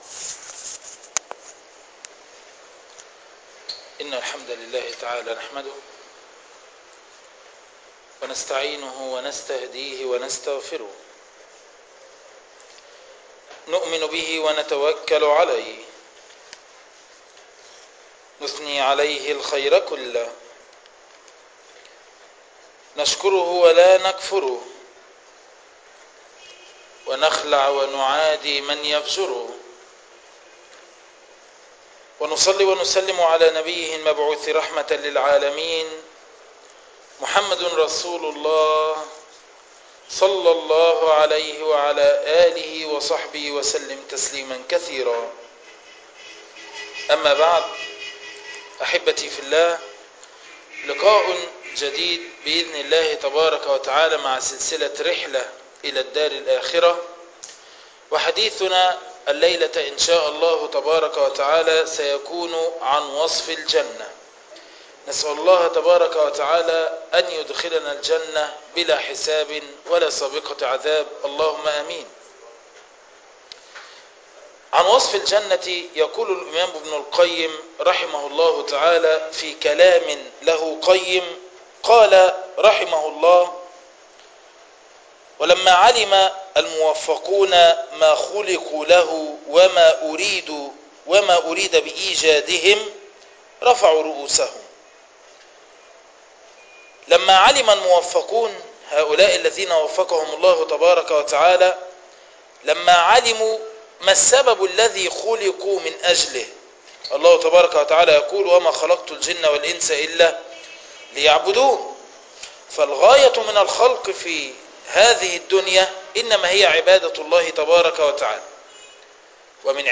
تفاصيل المادة عنوان المادة وصف الجنة الدرس الأول تاريخ التحميل الخميس 3 مايو 2012 مـ حجم المادة 18.67 ميجا بايت عدد الزيارات 946 زيارة عدد مرات الحفظ 322 مرة إستماع المادة حفظ المادة اضف تعليقك أرسل لصديق